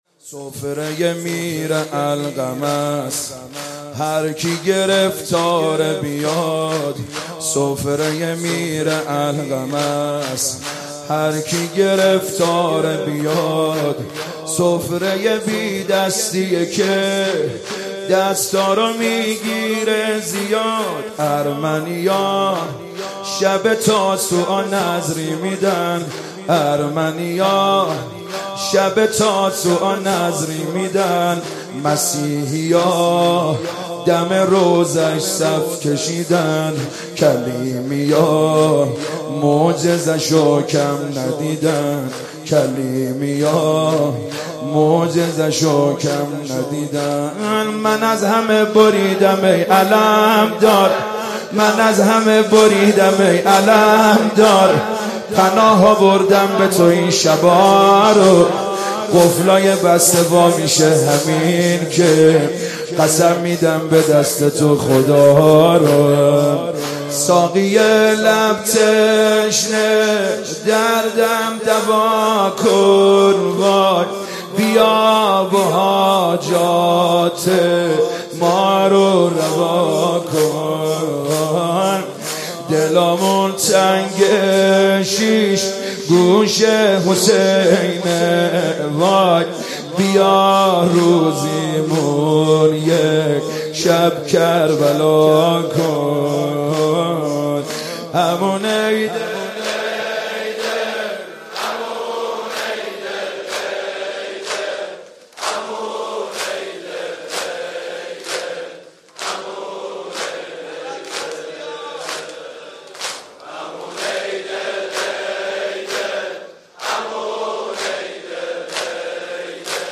شب نهم محرم97 شب تاسوعا هیات کربلا رفسنجان
سه ضرب